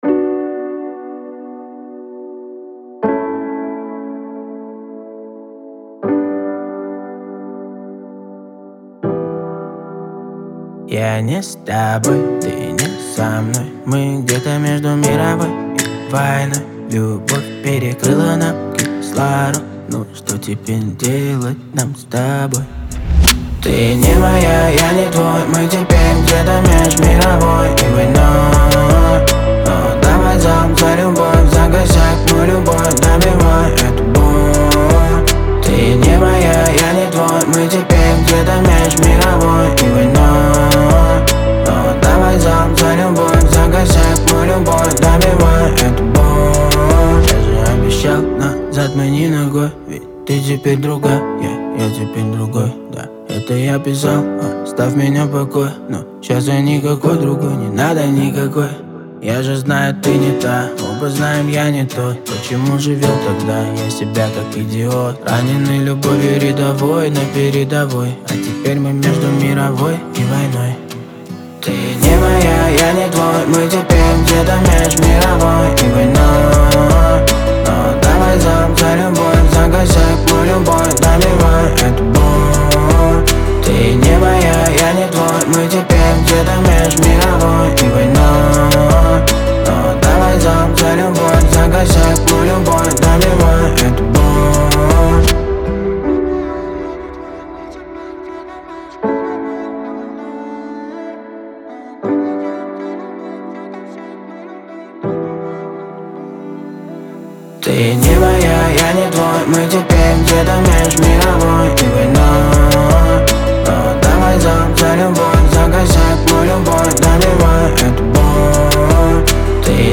выполненная в жанре поп-рок с элементами электроники.